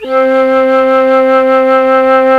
FLT FL VB 0F.wav